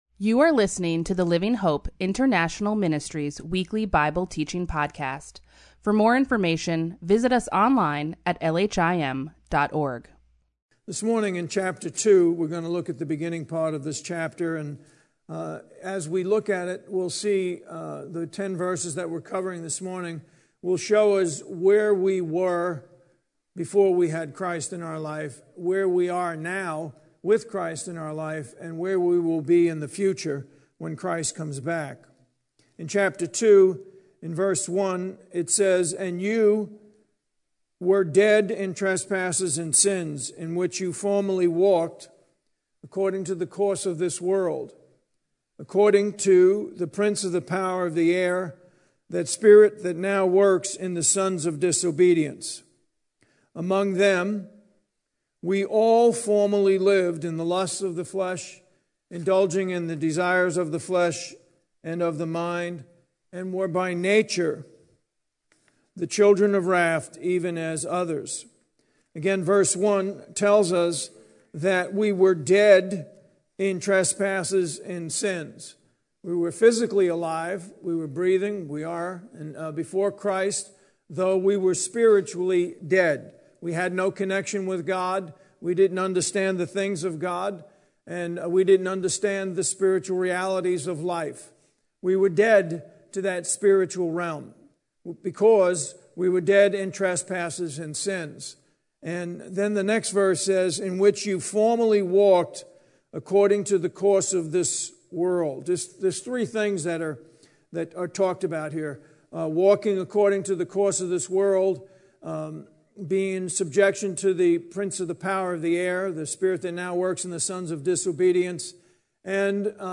Bible Teaching